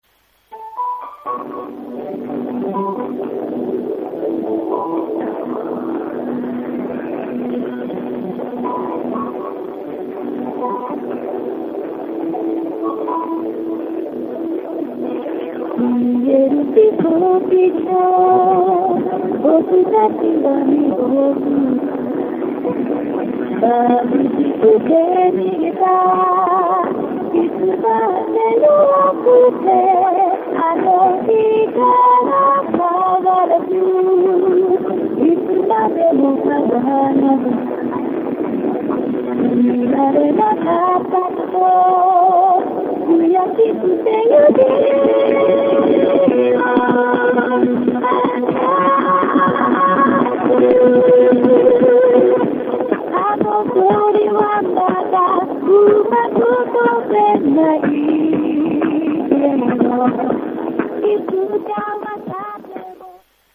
キャリア別通話音質
実際の通話を録音しました。
RealPlayer   声のみ抽出。結構滑らか。
初代ハーフ端末使用。